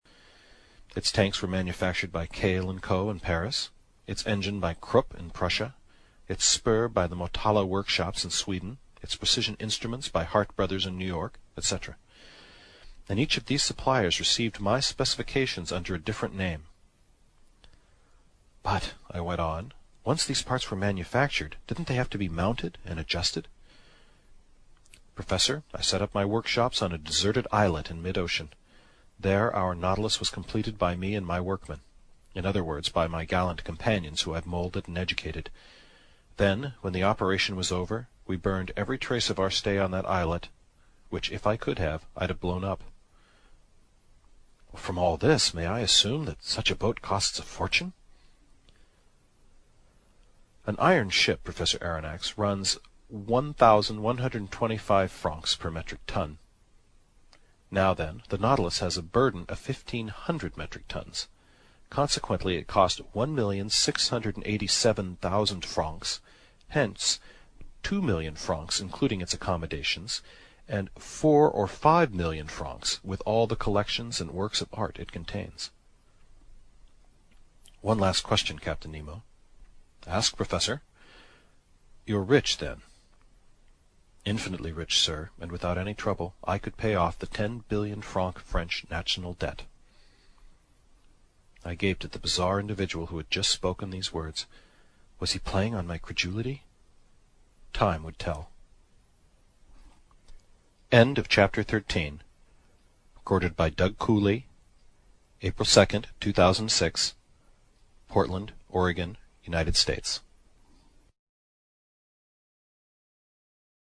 英语听书《海底两万里》第185期 第13章 一些数字(8) 听力文件下载—在线英语听力室